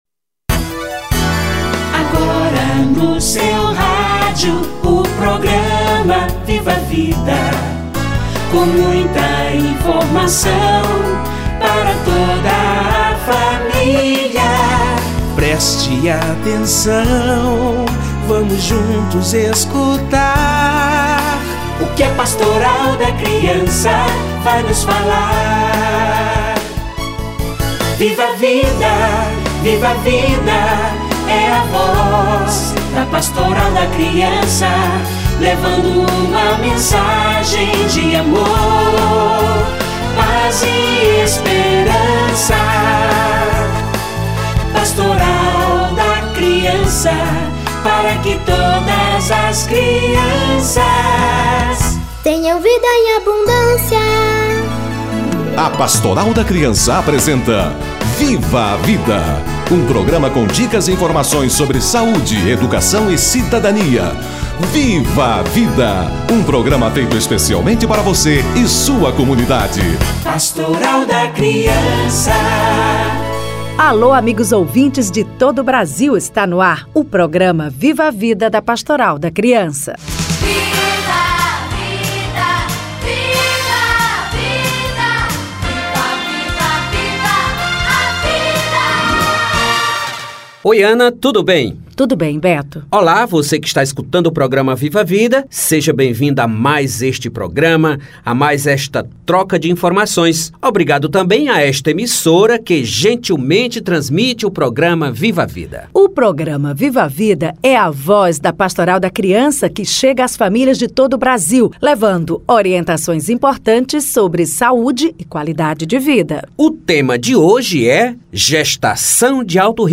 Cuidados gestantes - Entrevista